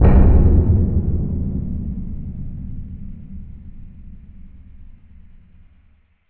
Impact 22.wav